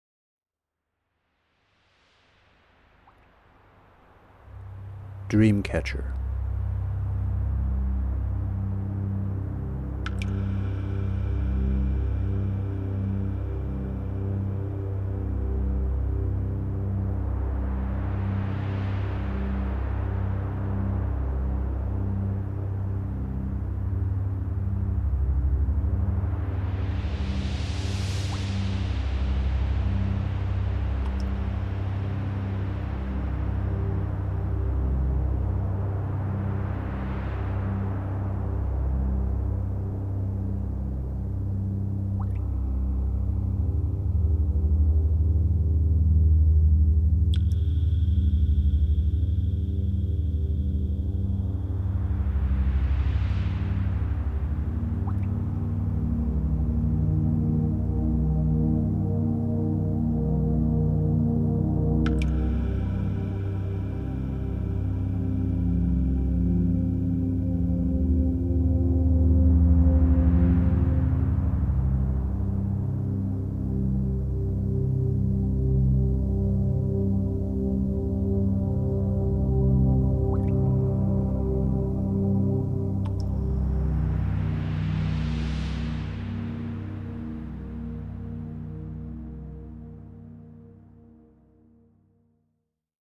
気分をなだめる雰囲気の音楽、穏やかな水の音
使用楽器：ハーモニックコード、ドローン、シンセサイザー、水の音と鯨の鳴き声の録音　５５分